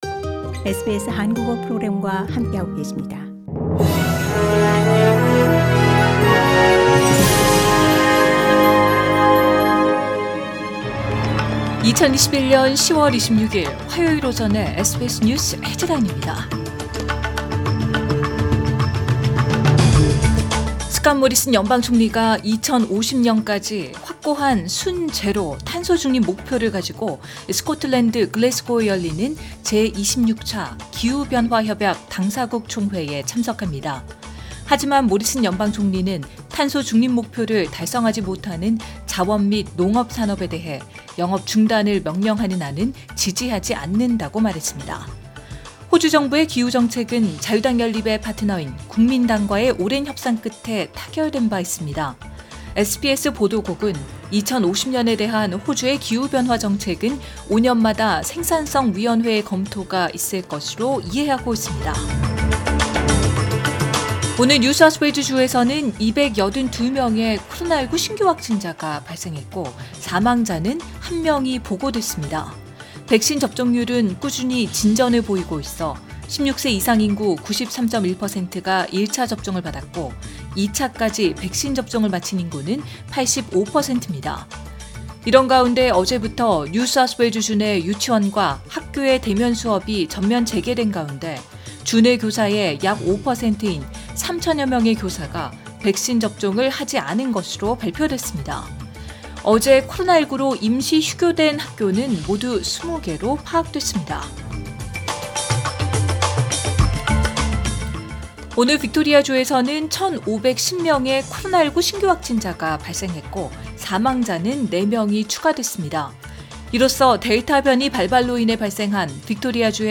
2021년10월 26일 화요일 오전의 SBS 뉴스 헤드라인입니다.